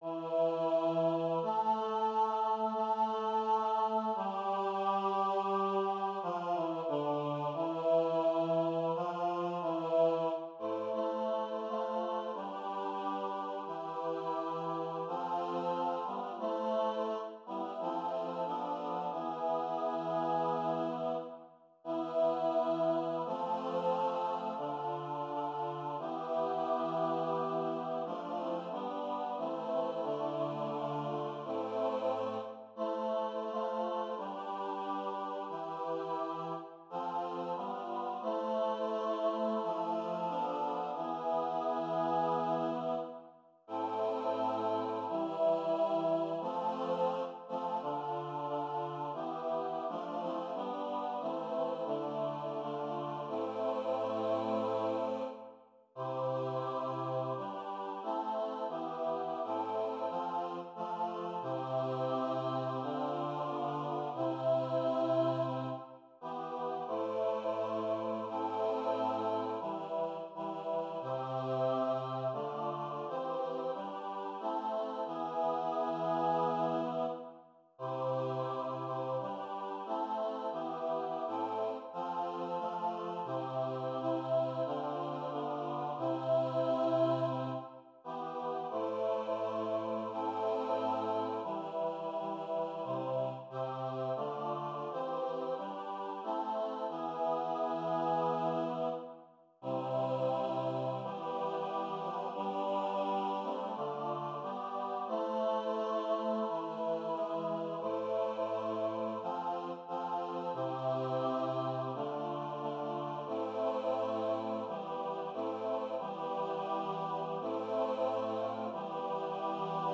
SATB
Voicing/Instrumentation: SATB